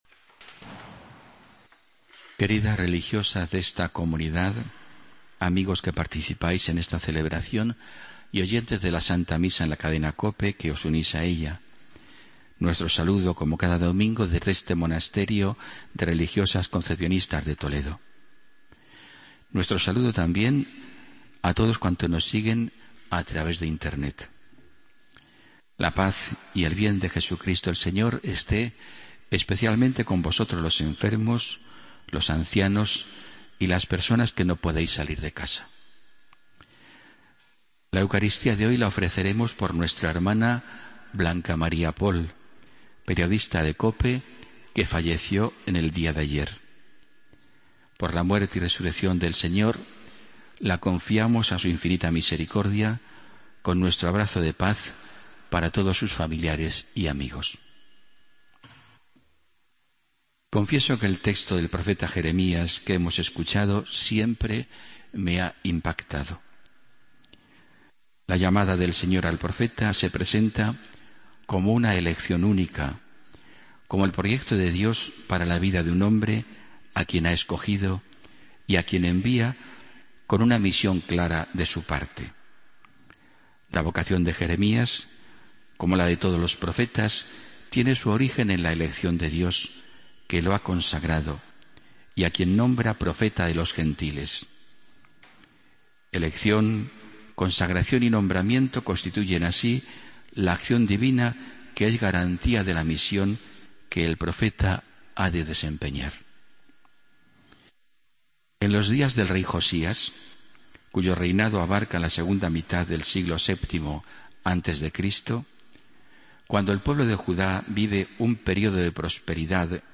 Homilía de domingo 31 de enero de 2016